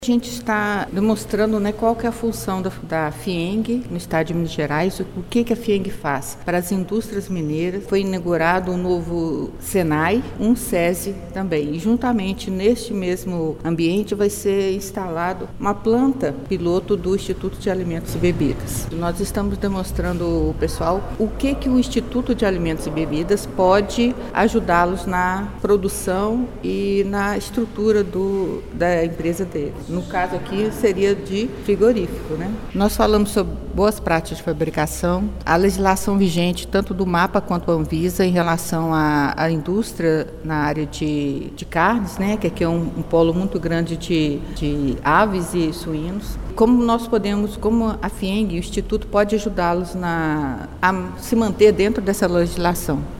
A Federação das Indústrias do Estado de Minas Gerais (FIEMG), por meio do Serviço Nacional de Aprendizagem Industrial (SENAI) promoveu um evento voltado para a indústria de alimentos.